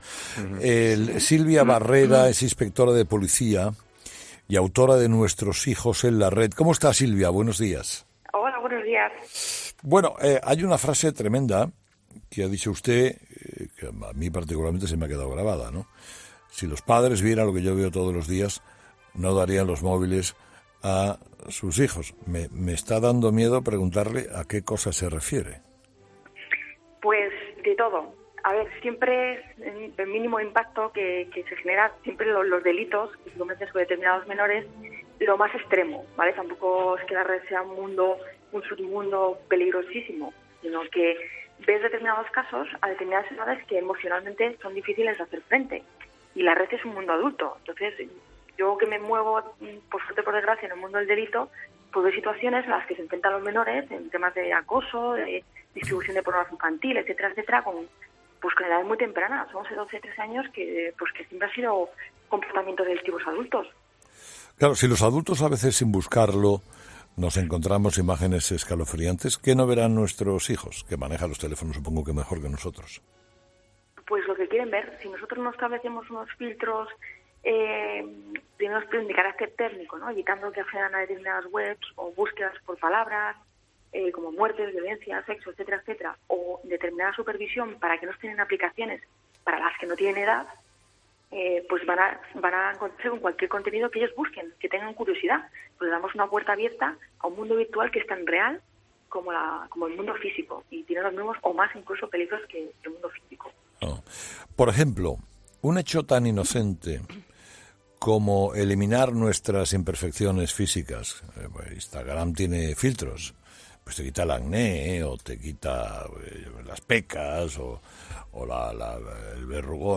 Últimas Noticias/Entrevistas